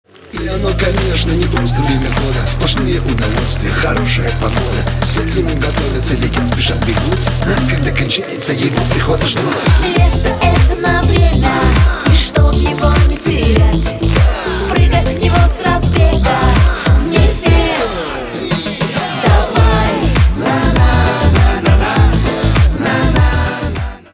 поп
позитивные